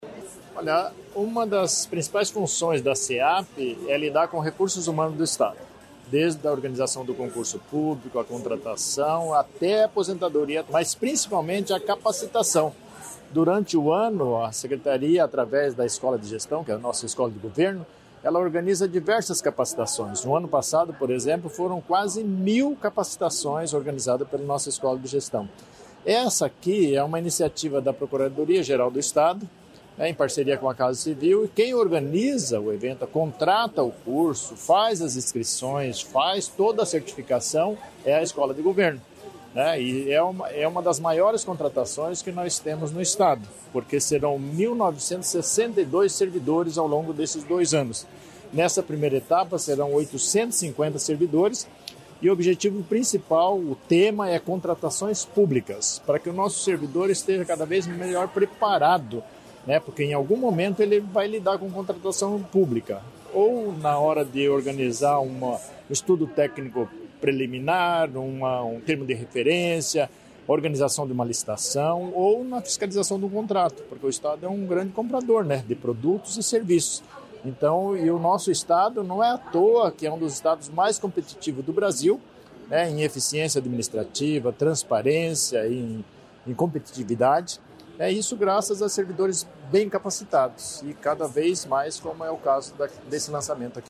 Sonora do secretário da Administração, Luizão Goulart, sobre a capacitação de servidores